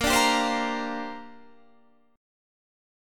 Bb6add9 chord